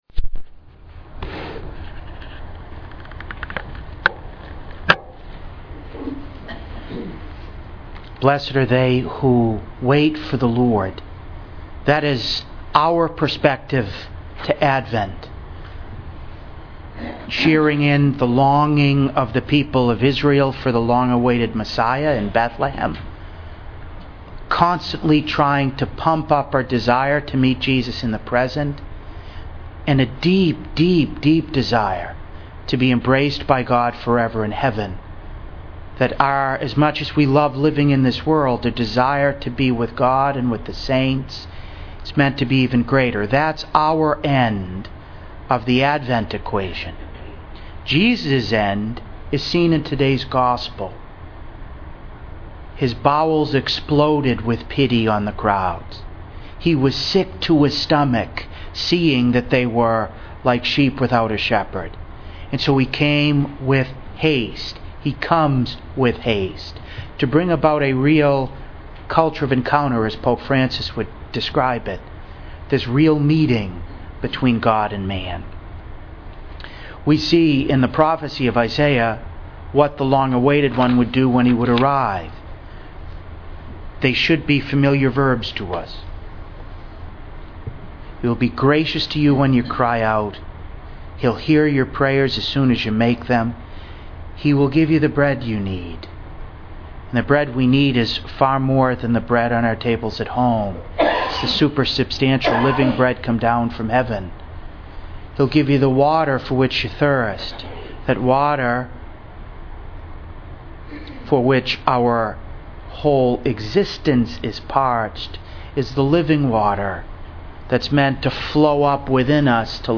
To listen to an audio recording of today’s homily, please click below: